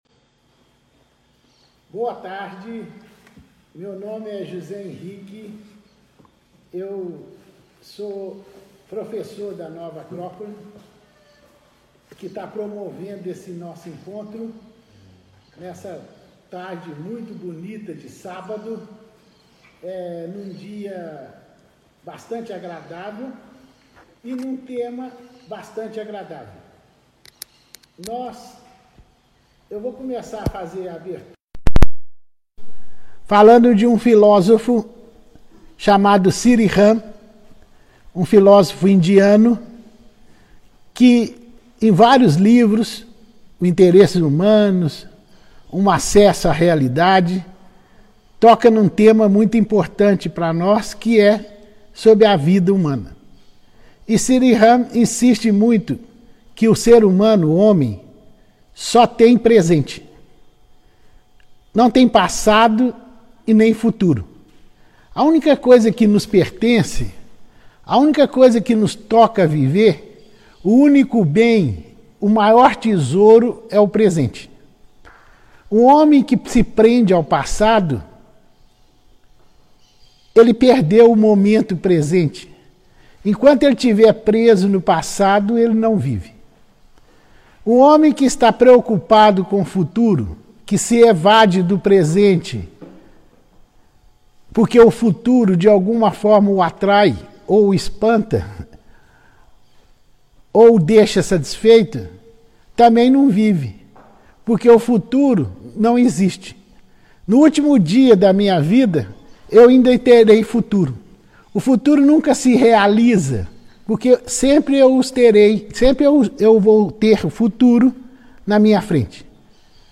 Lições sobre a Roma antiga Live